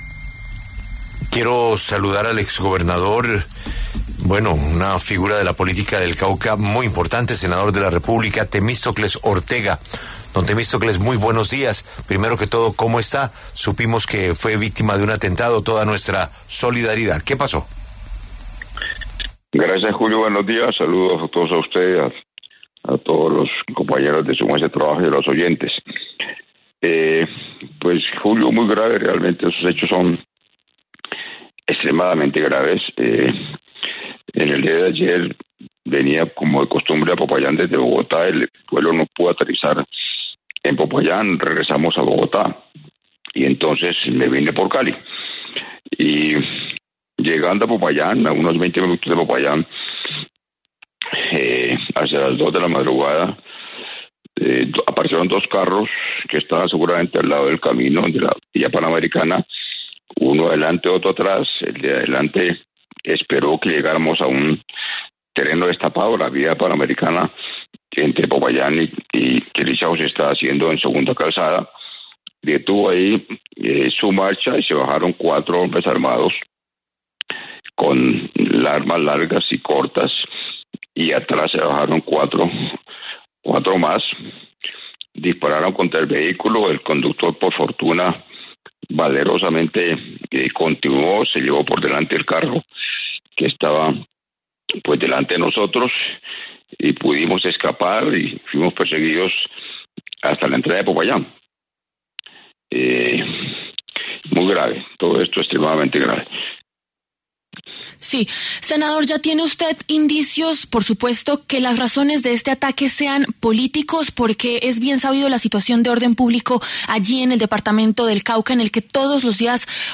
Así lo confirmó en los micrófonos de La W.